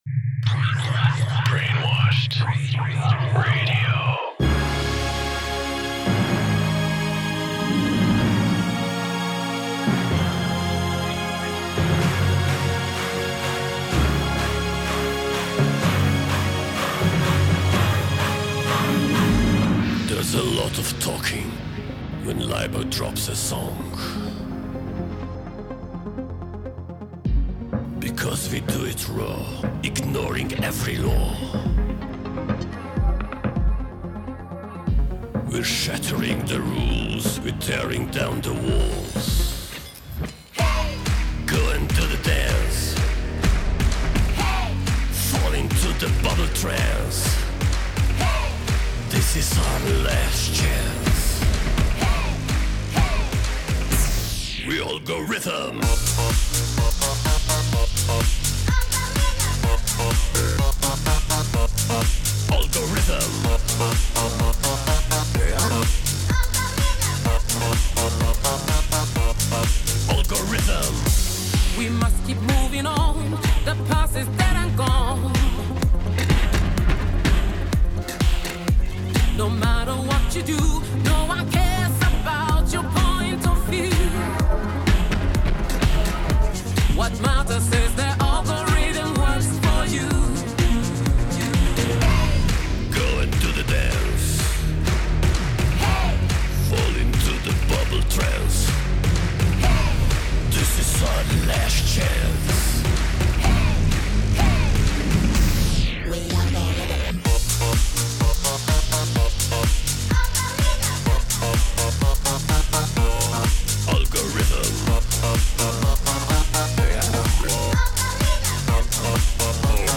Ecclectic music styles featuring artists